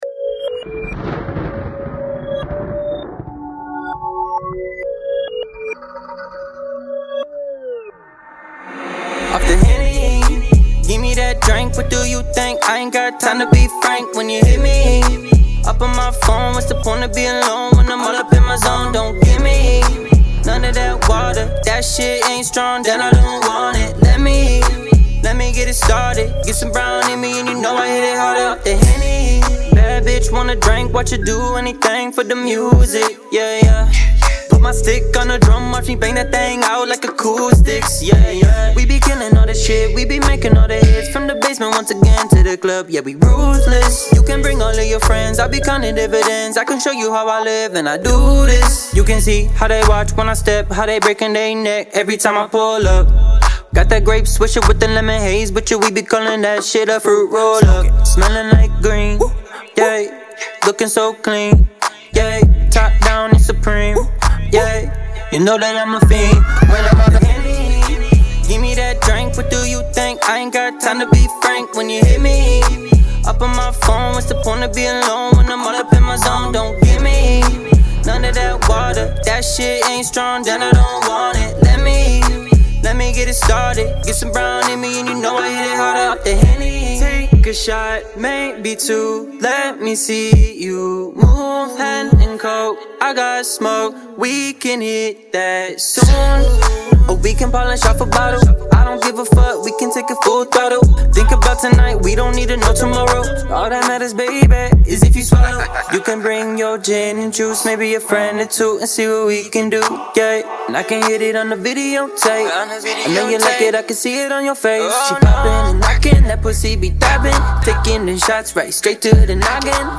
Pop
A club ready track that the ladies will love.